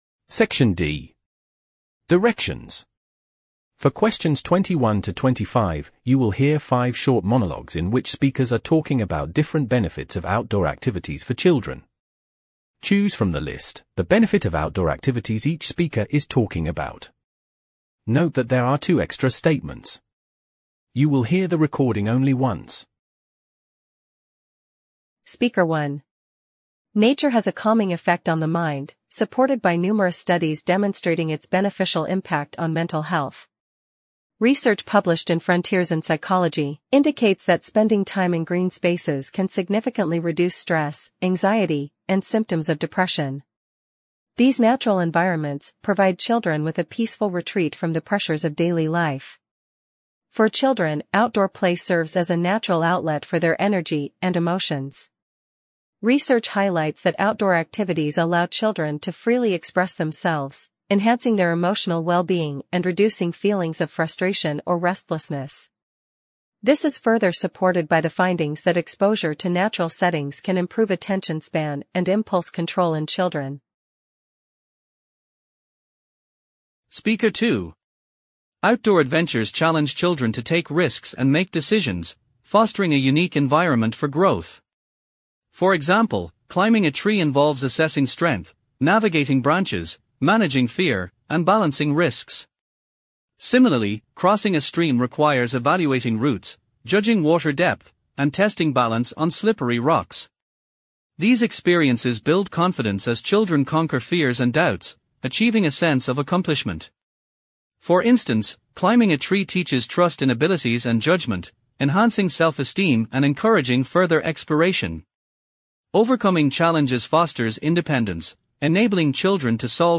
directions: For Questions 21 to 25, you will hear five short monologues in which speakers are talking about different benefits of outdoor activities for children.